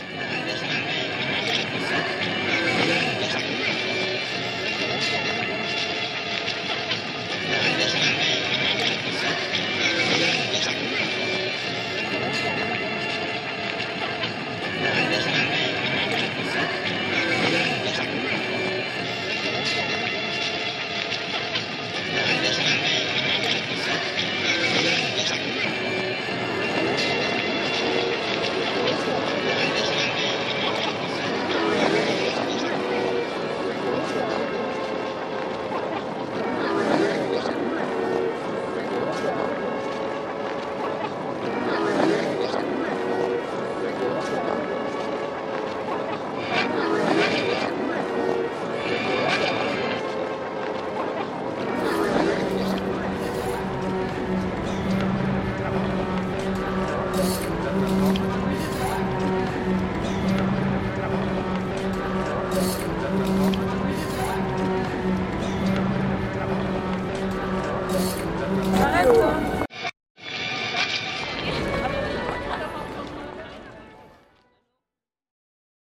Remix of the Louvre Courtyard record